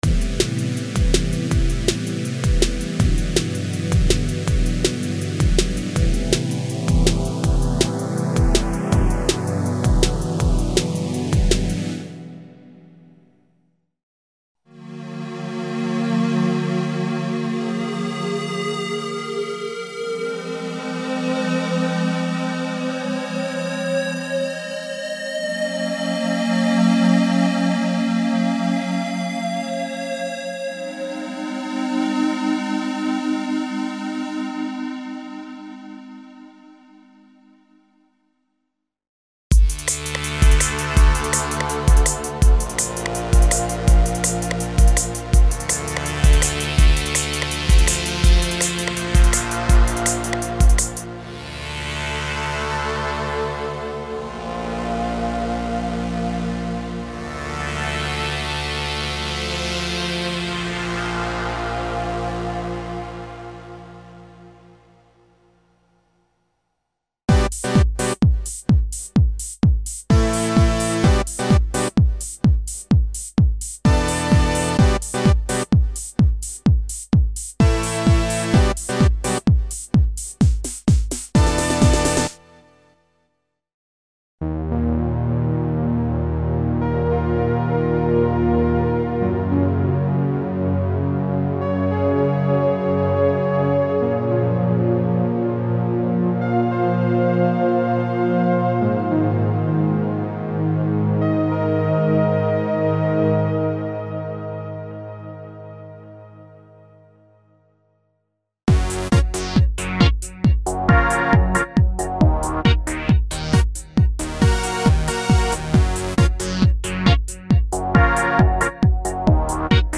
Original collection of analog & digital filtered pads for a wide variety of music styles (Techno, House, Trance, Jungle, Rave, Break Beat, Drum´n´Bass, Euro Dance, Hip-Hop, Trip-Hop, Ambient, EBM, Industrial, etc.).
Info: All original K:Works sound programs use internal Kurzweil K2500 ROM samples exclusively, there are no external samples used.